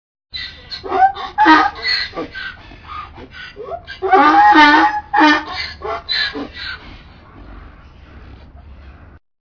دانلود آهنگ خر در طویله از افکت صوتی انسان و موجودات زنده
دانلود صدای خر در طویله از ساعد نیوز با لینک مستقیم و کیفیت بالا
جلوه های صوتی